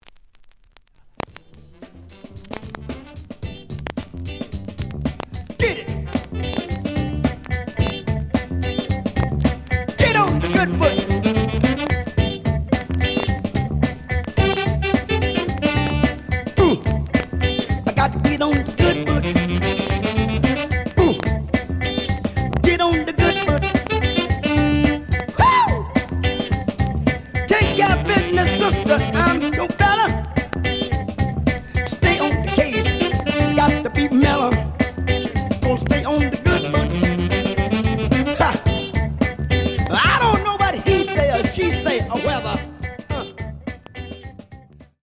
The audio sample is recorded from the actual item.
●Genre: Funk, 70's Funk
Some noise on both sides due to scratches.)